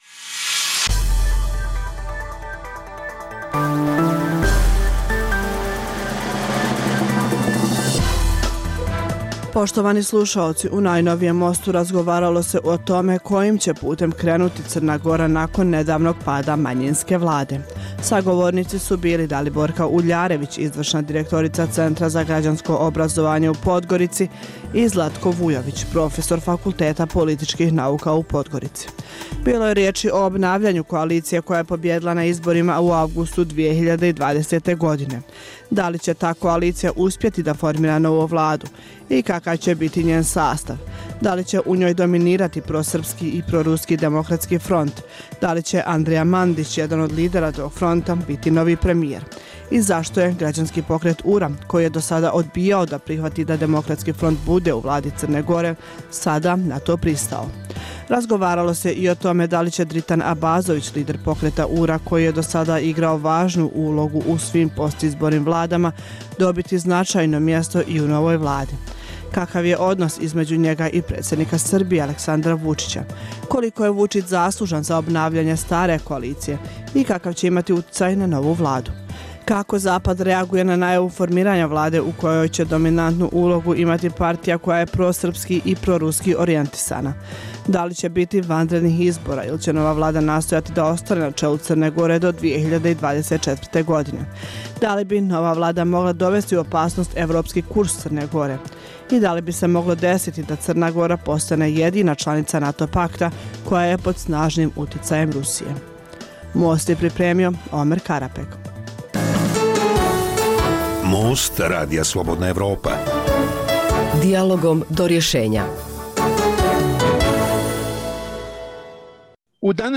Dijaloška emisija o politici